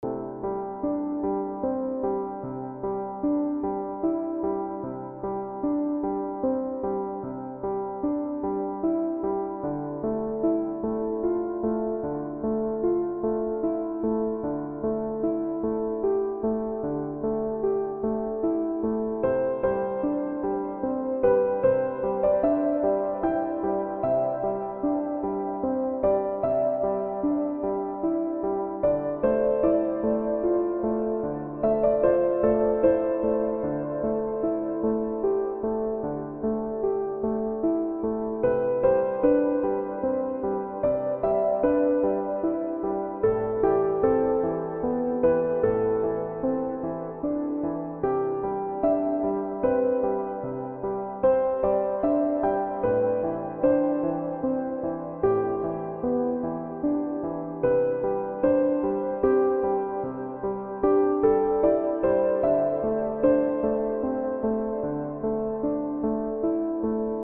Test Klaviermusik